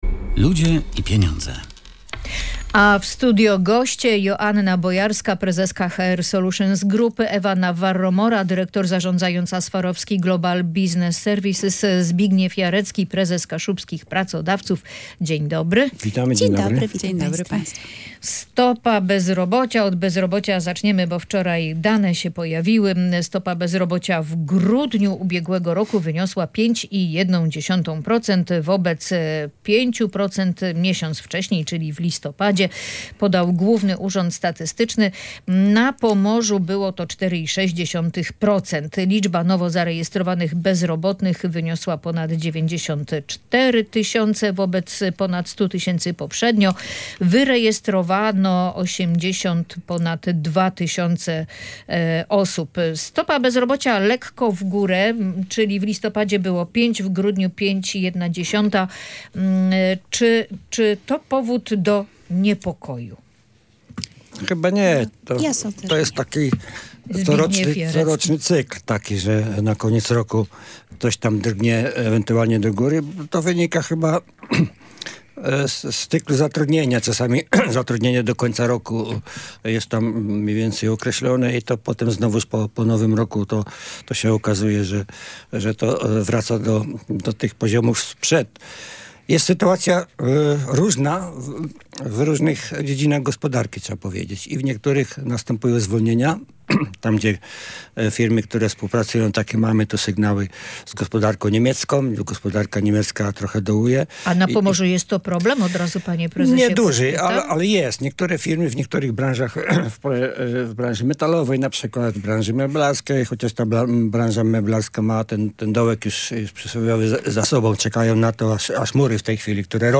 Między innymi na ten temat dyskutowali goście audycji „Ludzie i Pieniądze”: